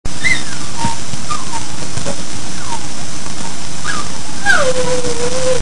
柴犬の雑種
散布をねだって催促するとき
テープからのマイク録音のため、
ちょっと音質が悪いのですが､
キューンクーン　6sec　36kB）